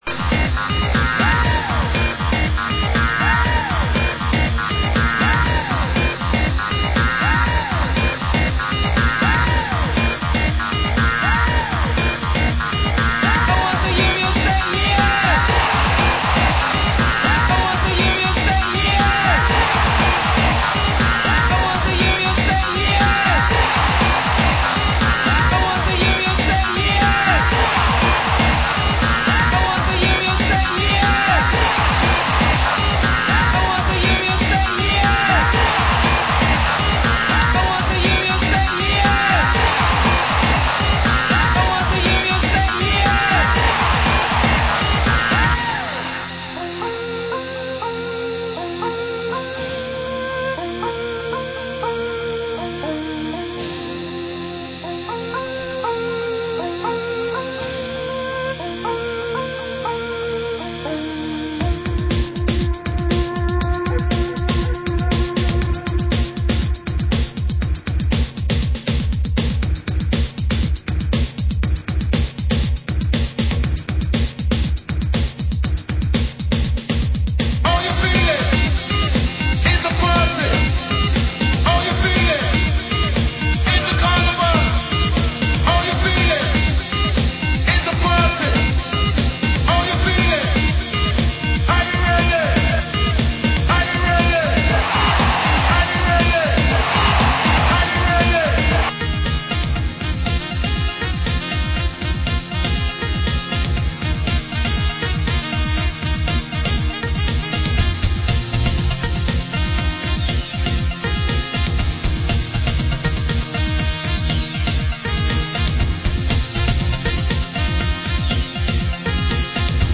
Format: Vinyl 12 Inch
Genre: UK Techno